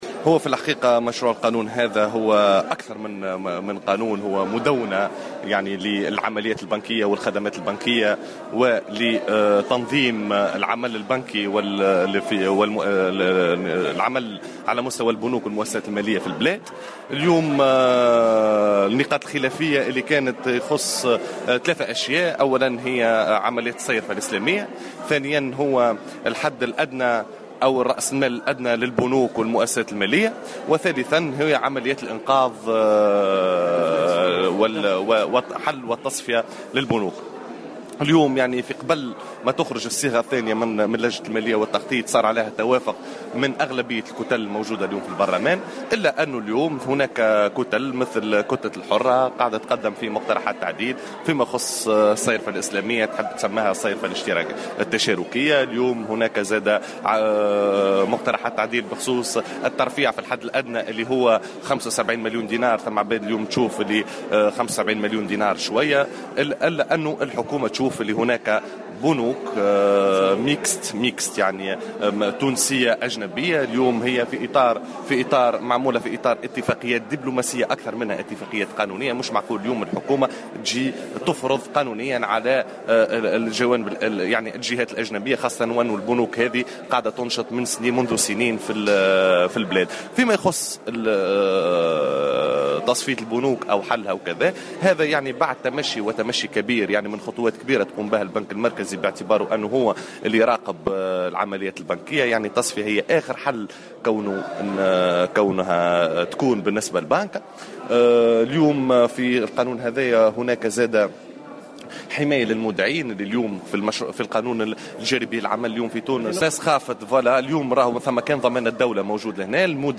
شكيب باني، مقرر لجنة المالية، في تصريح لمراسل الجوهرة أف أم